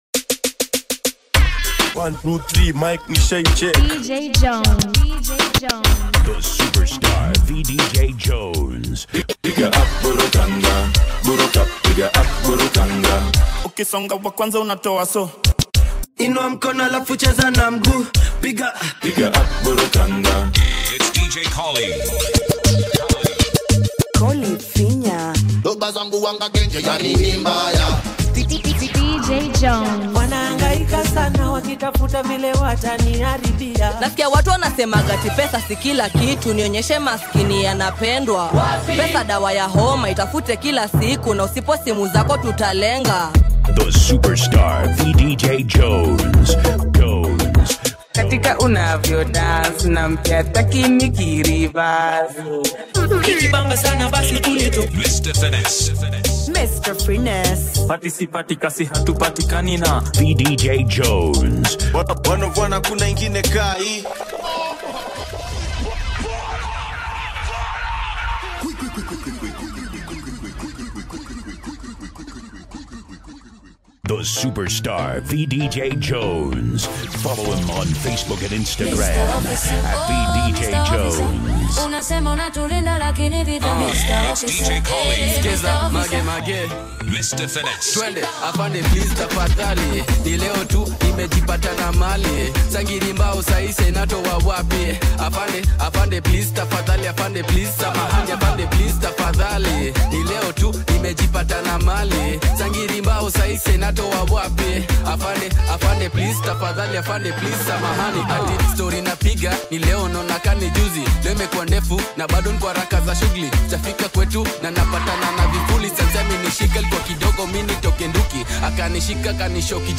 It suits anyone who loves loud sound and fast transitions.